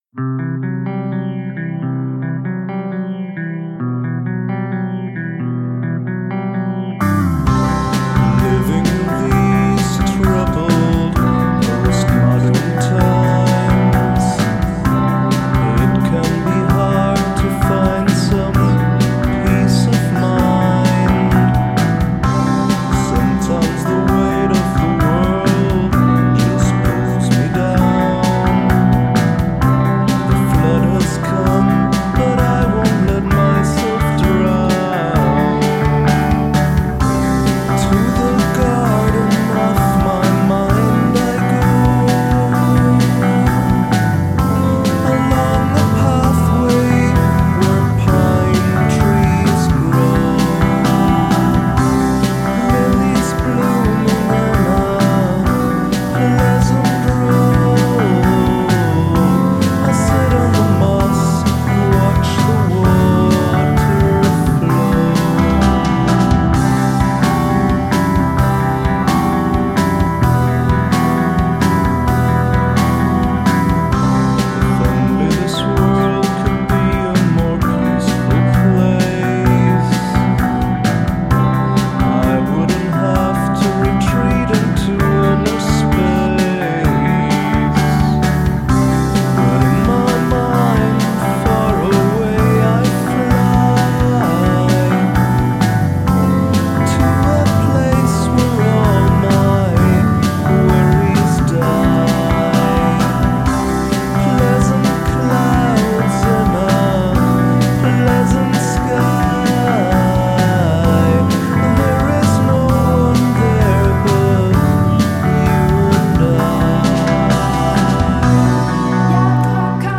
Vox is buried just a bit but works well with the lyric.